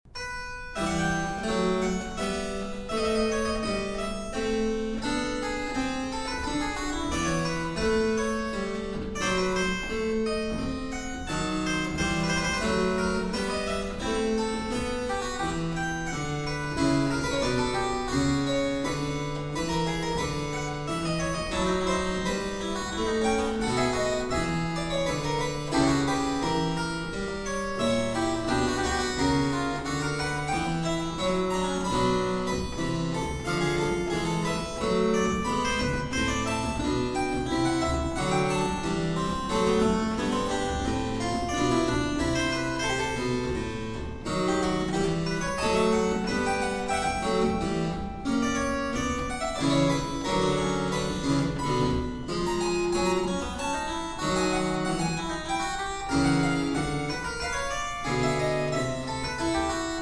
Pedal Harpsichord, Organ